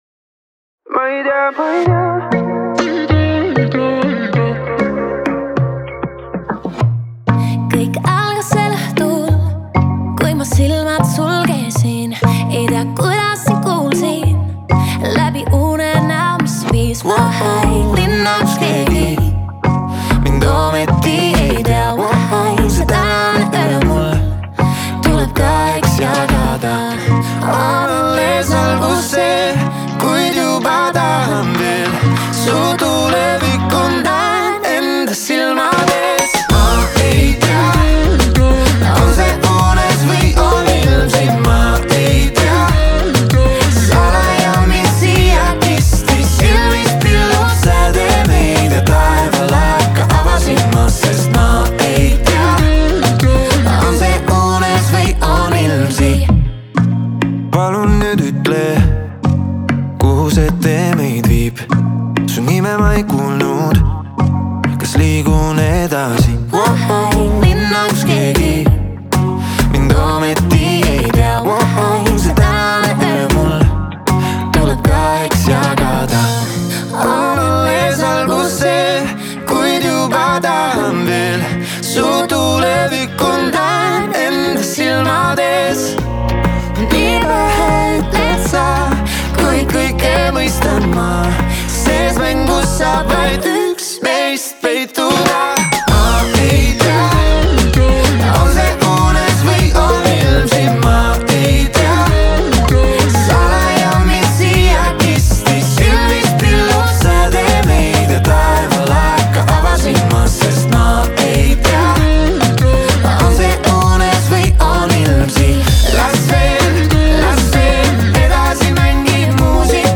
это яркая и энергичная песня в жанре поп